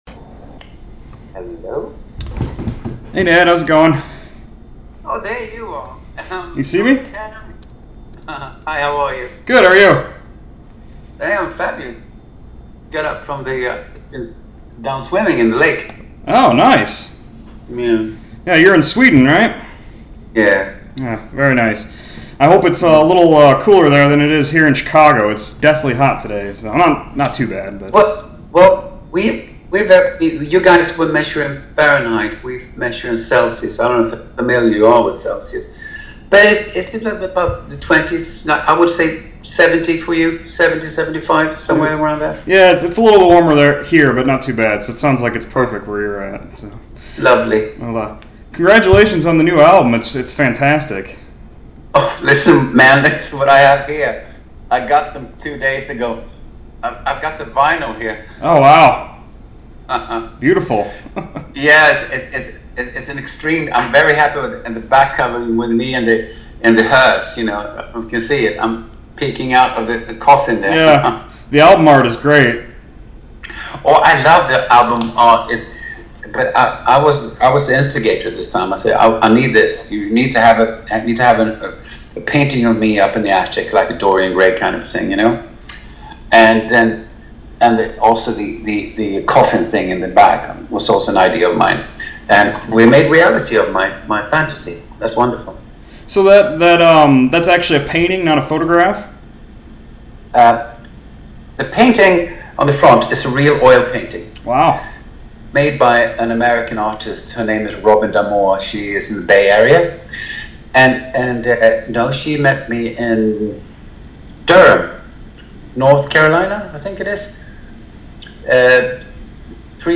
The Vampirate Speaks: A Conversation With Nad Sylvan
nad-sylvan-interview-6-28-19.mp3